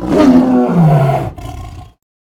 combat / creatures / tiger / she / die1.ogg